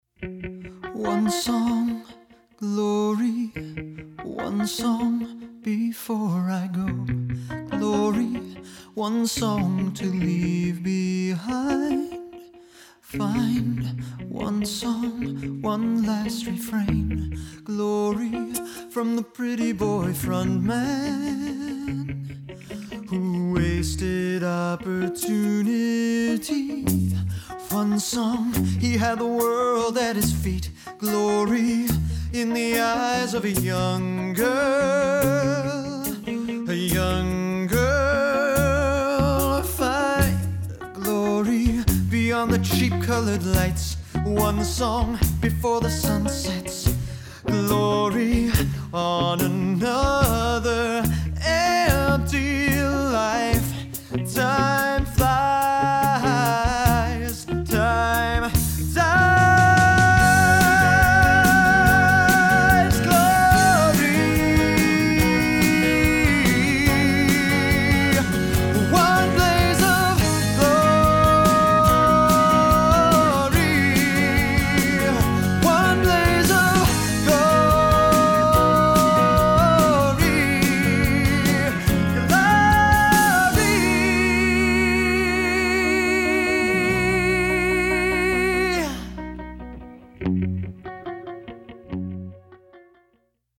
Broadway